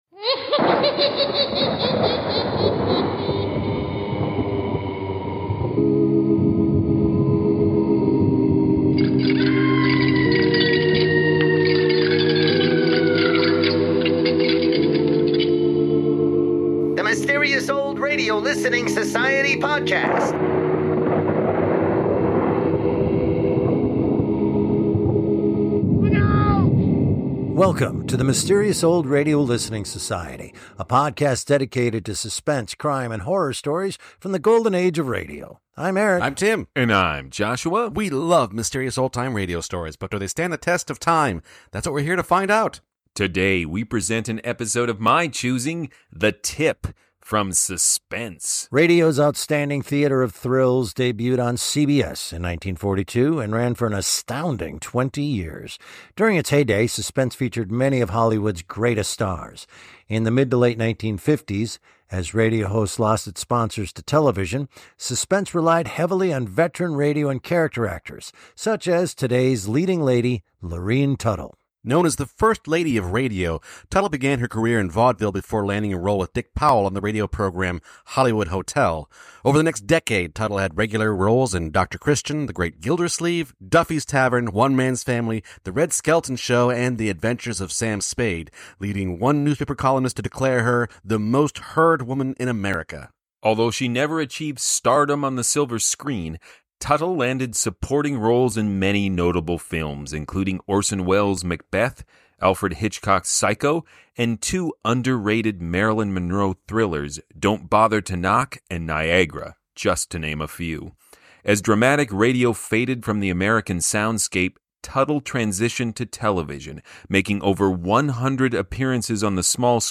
In this production, she plays a devoted wife being held at gunpoint by a desperate man.